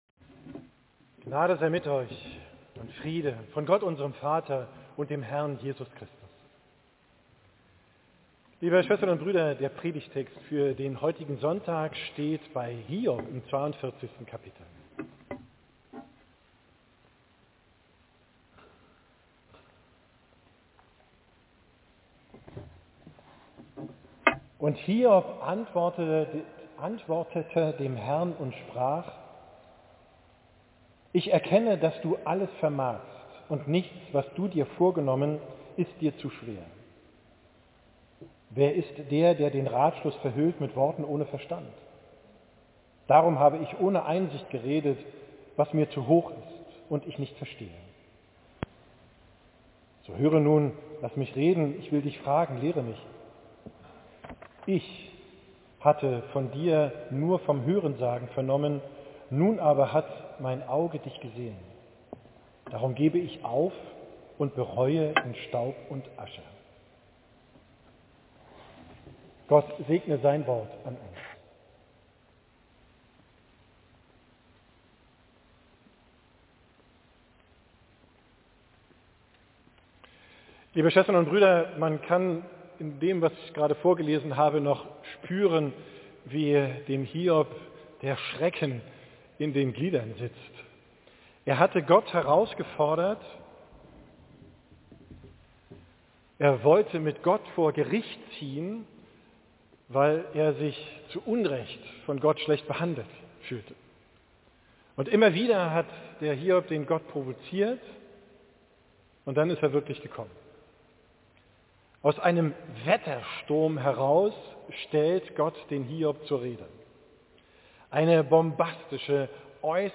Predigt vom 1.